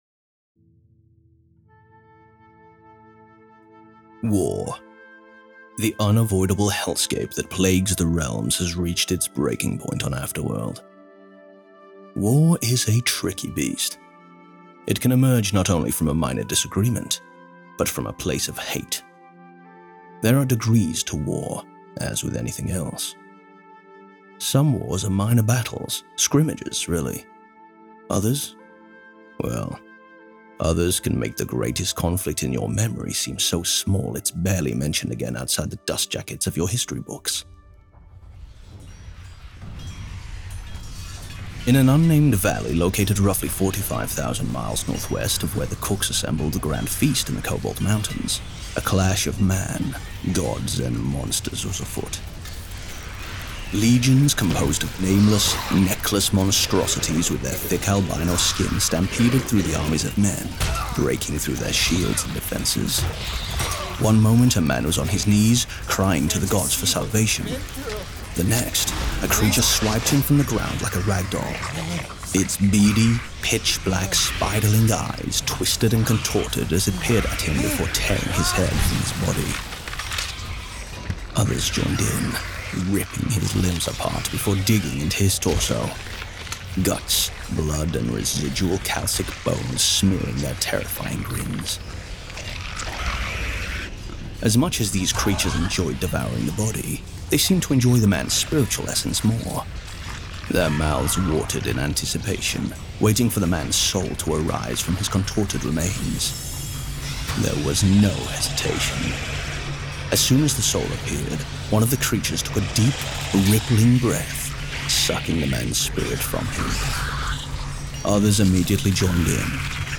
2024 Goals and an Exclusive Audiobook Sneak Peek
1. My first and most important goal is to finish the audiobook for Dawning of Darkness by March. I've put a lot of work into it, and the cast is superb.
Psst, yours truly does the audio editing and sound mixing.
If you're not into full cast audiobooks, please check out the ebooks and paperback/hardcovers (where available) for the rest of the series!